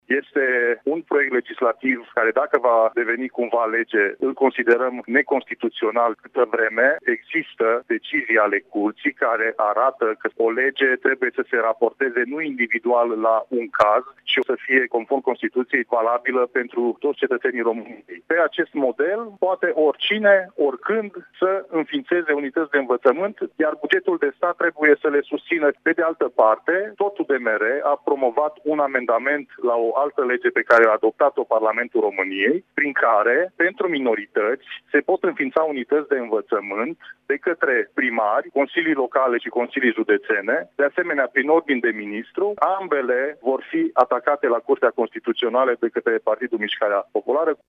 Deputatul PMP Mureș, Marius Pașcan: